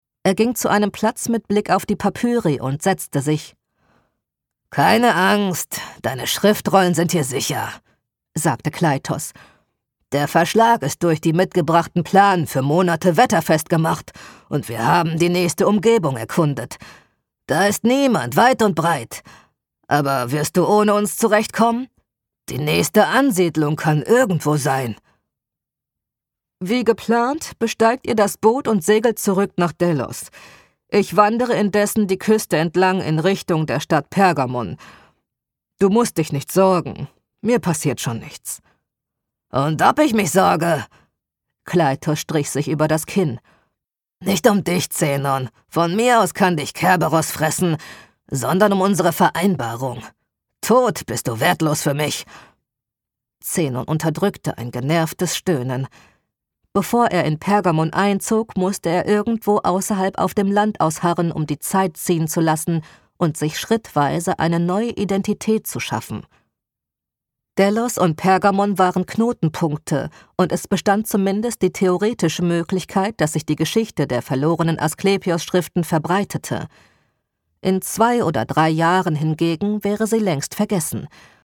Hörbuch - Historisch
Hoerbuch_hystorisch_maennlich.mp3